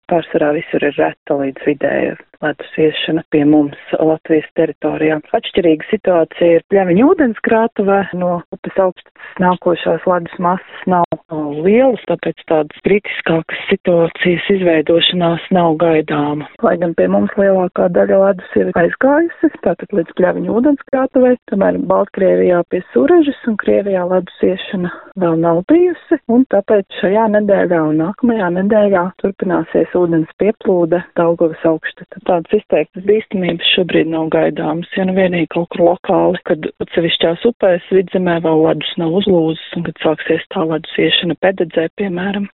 Hidroloģe: Šis pavsaris ir bijis saudzīgs, neradot lielus plūdus